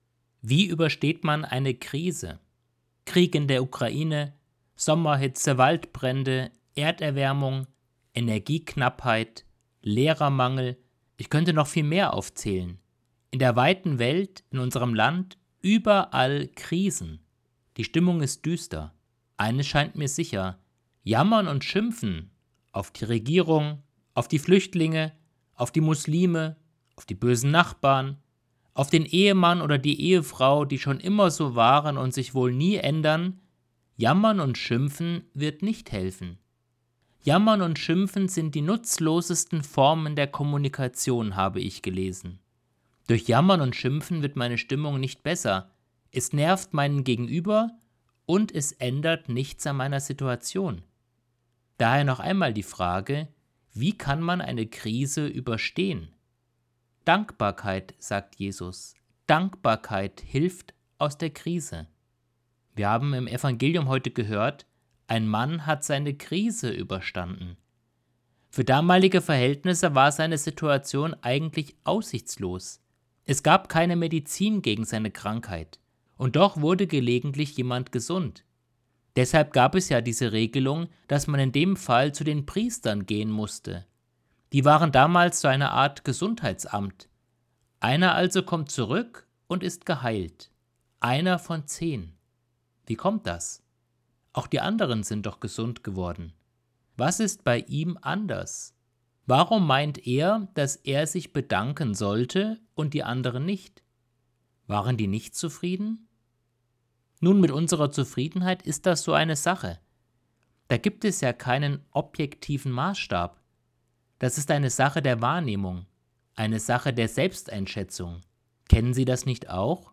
Predigt vom 12.10.2025